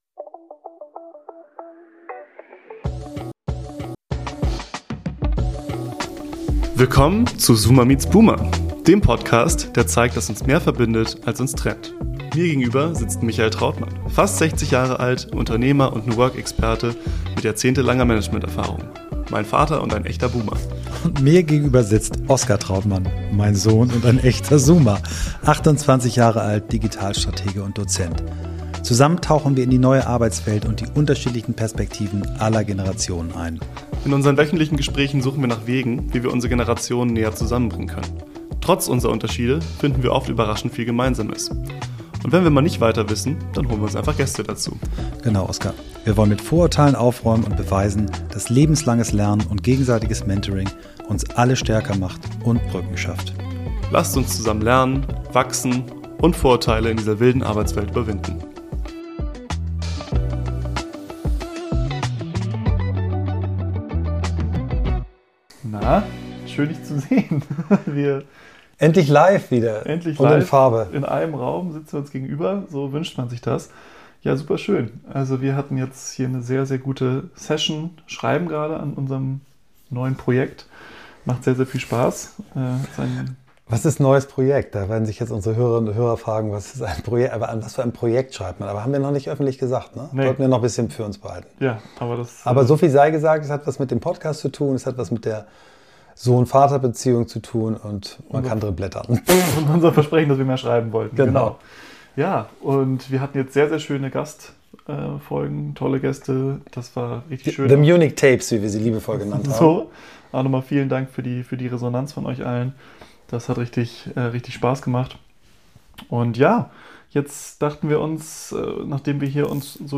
Nach unseren Munich Tapes und mehreren starken Gastfolgen sitzen wir diesmal wieder live zusammen an einem Tisch.